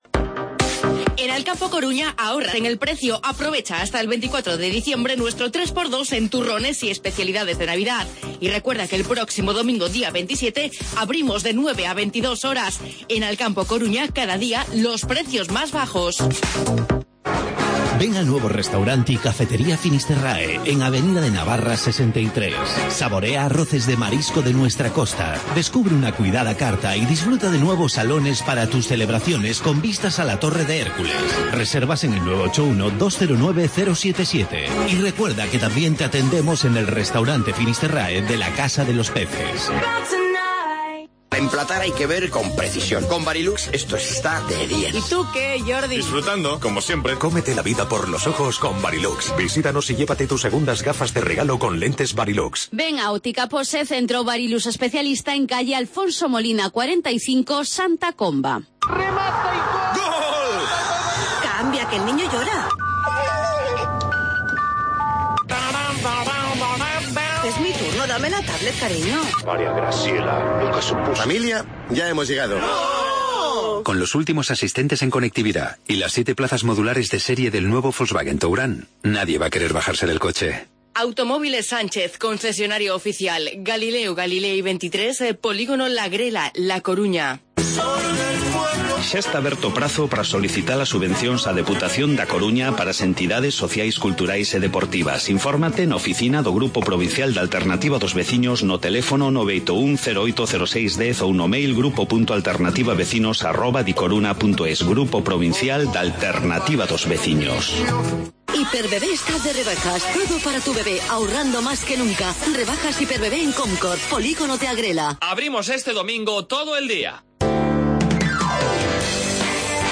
AUDIO: Los sonidos de la lotería de Navidad en A Coruña